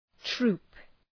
Προφορά
{tru:p}